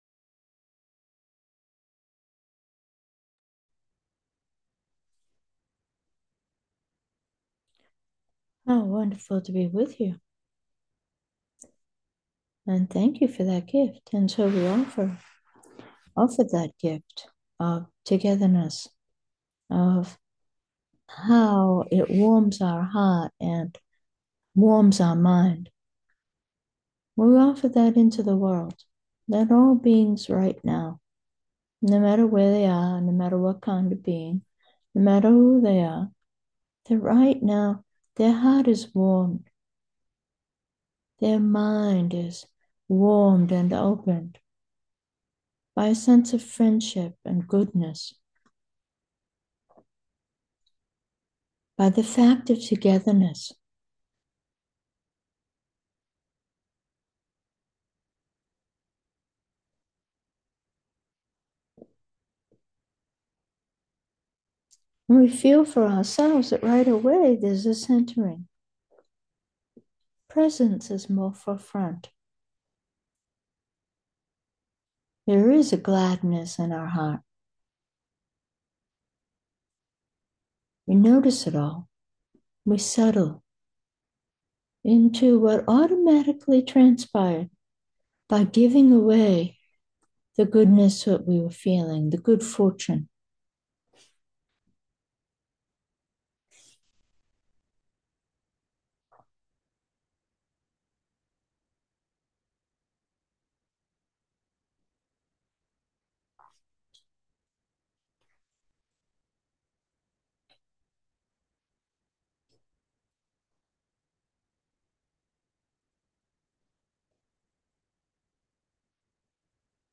The meditations done here have the feature of in-the-moment. The meditation itself uses the moment and group field, the teaching and supportive comments are generated by the shared experience of the moment, and none of that spontaneity will be possible for the podcast user/reader of this post if I write about what we did.